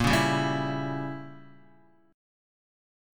A#dim7 chord